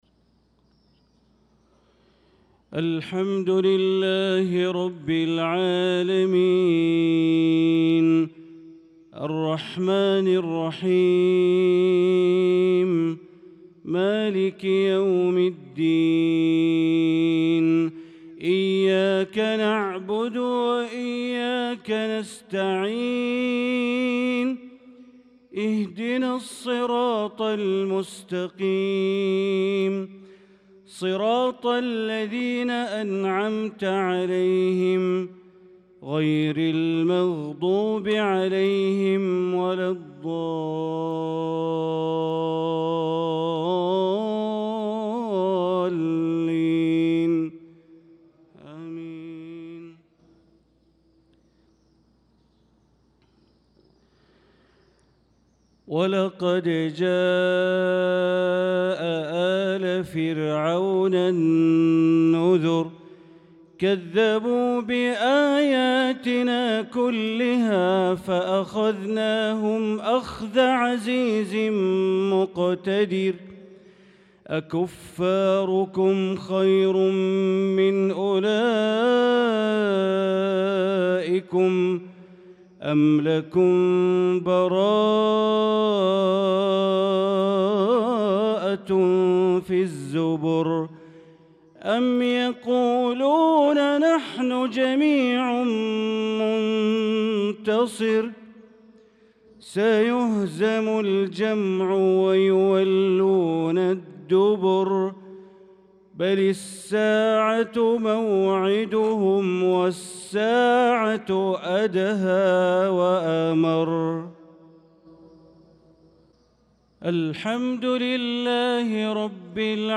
صلاة المغرب للقارئ بندر بليلة 2 ذو القعدة 1445 هـ
تِلَاوَات الْحَرَمَيْن .